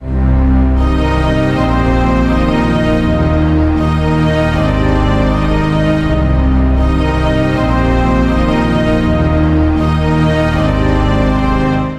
Soundtrack Strings Patriot 1
标签： 80 bpm Orchestral Loops Strings Loops 2.02 MB wav Key : Unknown
声道立体声